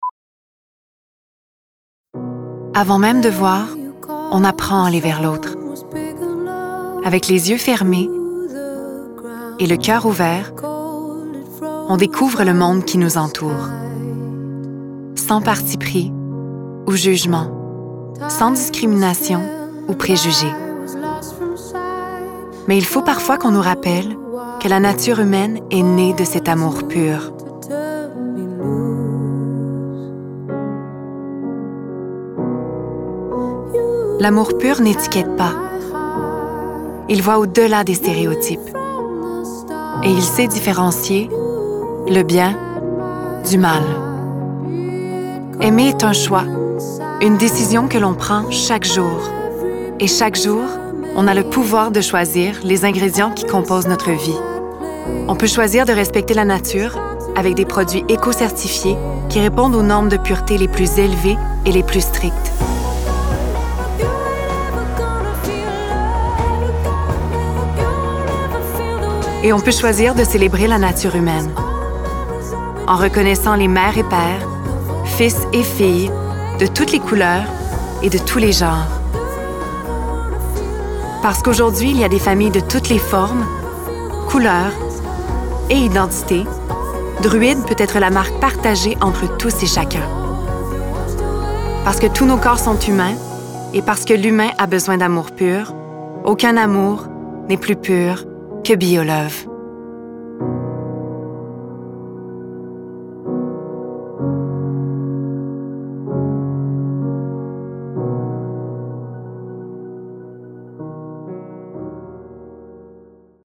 Démo Canada Druide
Voix off
20 - 40 ans - Mezzo-soprano